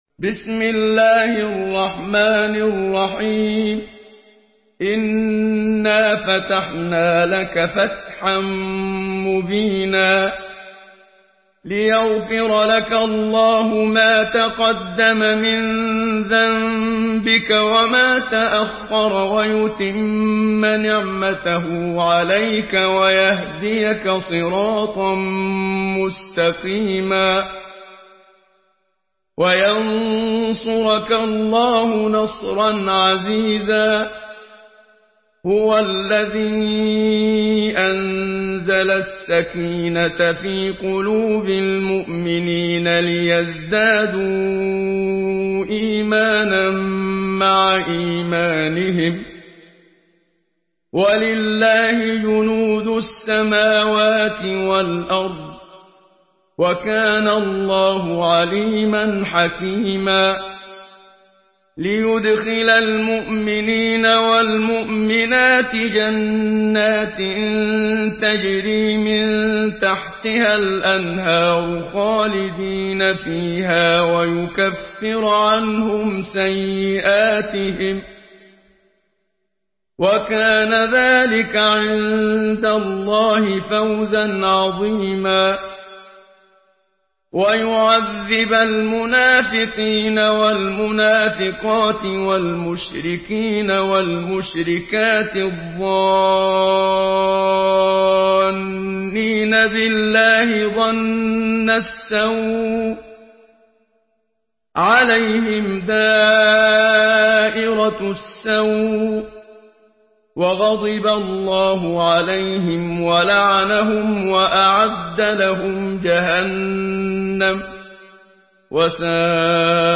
Surah الفتح MP3 by محمد صديق المنشاوي in حفص عن عاصم narration.
مرتل